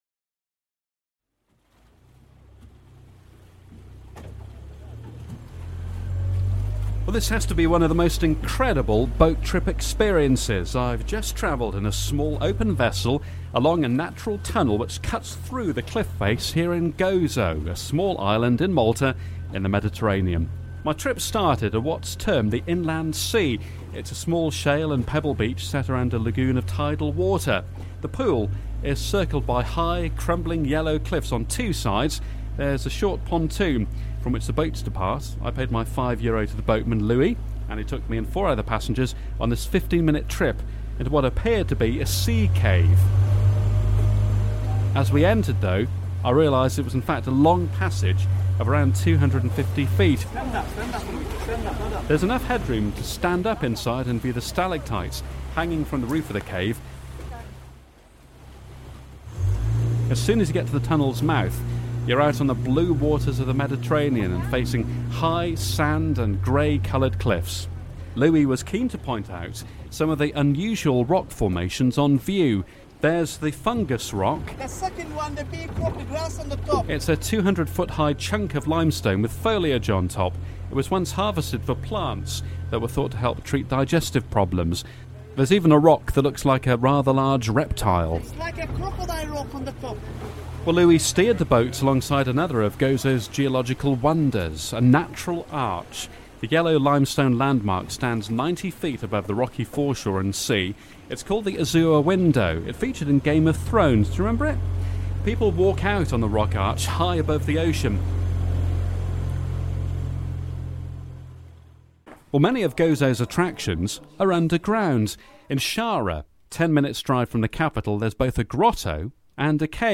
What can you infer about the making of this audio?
reports from Gozo on the UK Radio's Travel Show